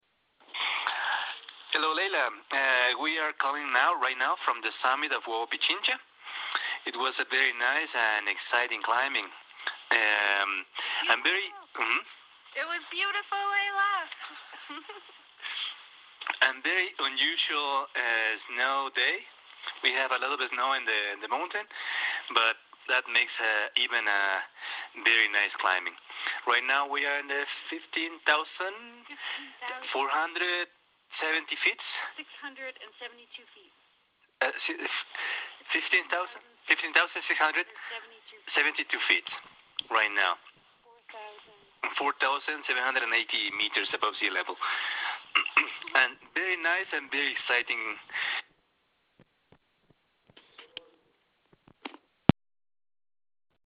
Ecuador Volcanoes Expedition Dispatch